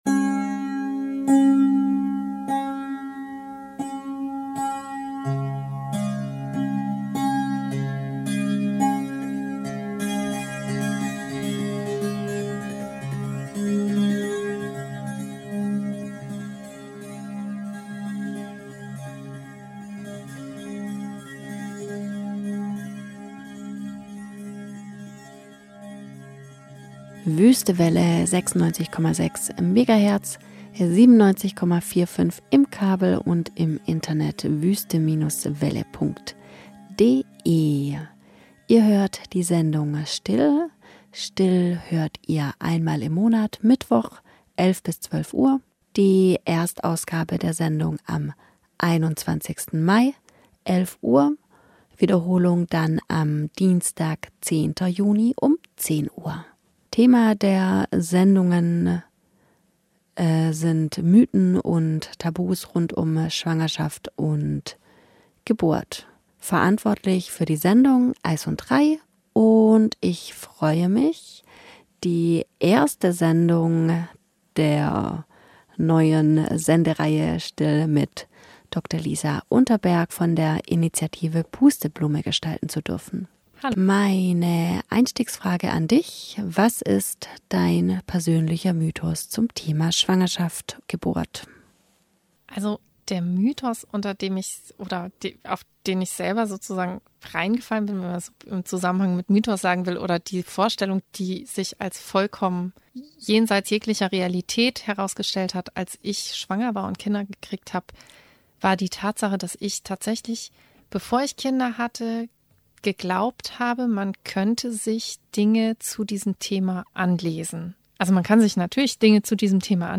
Die Initiative bietet Beratung Begleitung und Begegnungen für Familien mit Herausforderungen auf dem Weg zur Elternschaft. Herausforderungen wie Tod eines Kindes während der Schwangerschaft oder kurz nach der Geburt. Das Interview kann hier nachgehört werden.